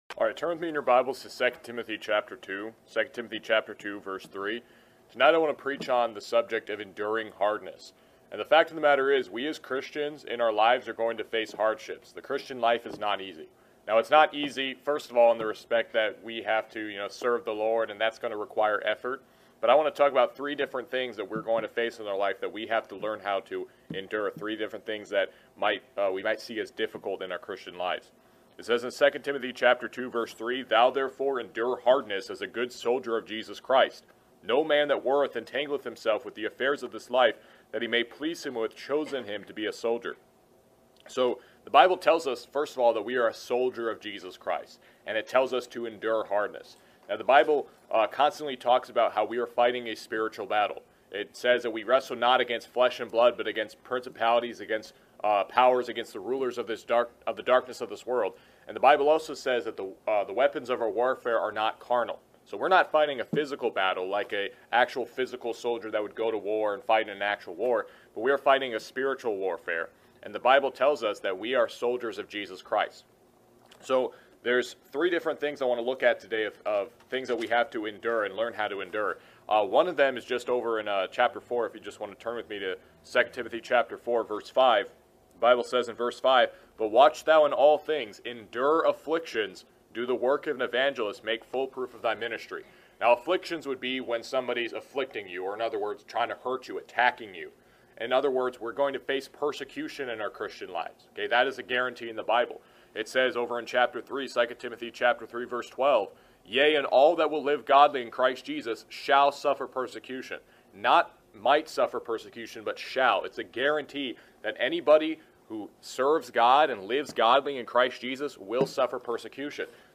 Every NIFB Sermon and More
FWBC Preaching Class 2022